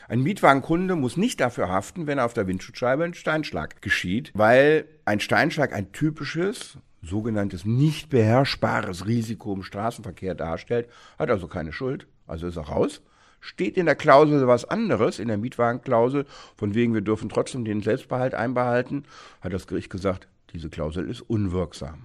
O-Ton: Kein Selbstbehalt bei Steinschlag – Mietwagenkunde erhält Geld zurück – Vorabs Medienproduktion